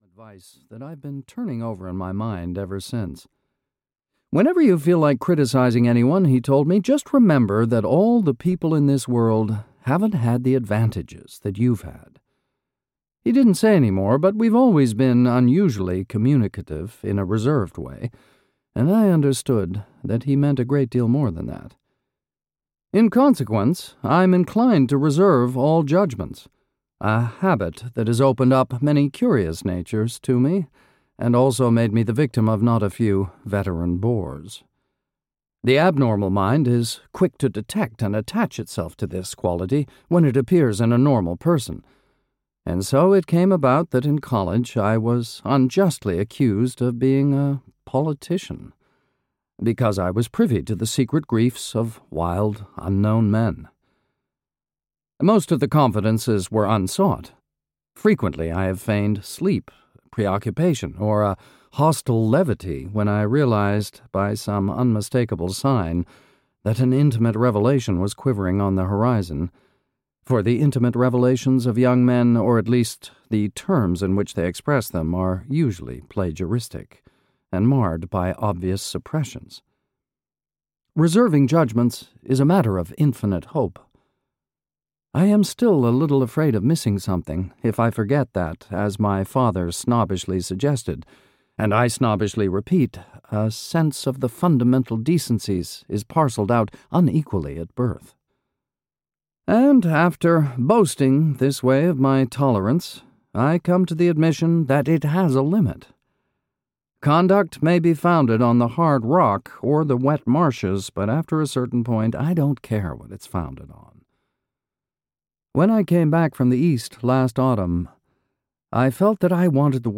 The Great Gatsby (EN) audiokniha
Ukázka z knihy